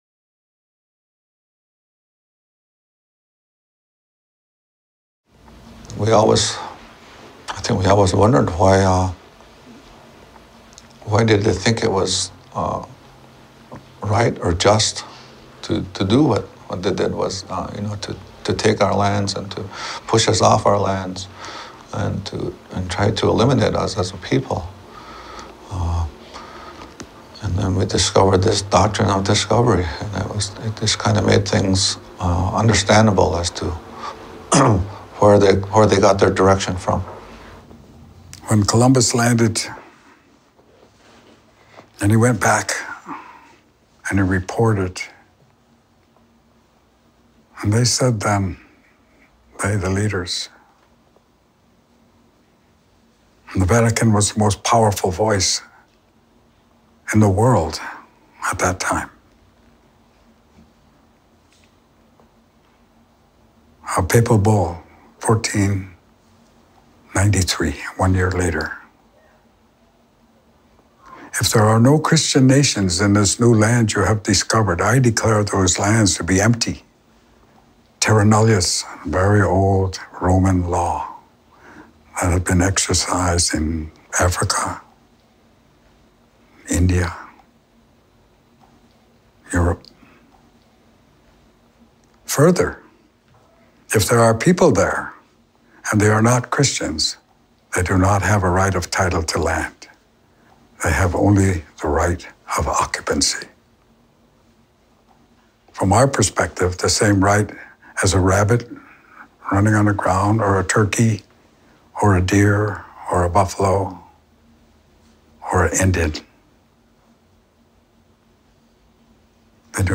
This short film is part of 8 short, testimonial films, on the Haudenosaunee (Iroquois.) The Iroquois are embarking on an historic project about the 500-year history of the Iroquois, their relationship with Europe and America and their prophesies that, if heard, can help us navigate the oncoming changes due to climate change.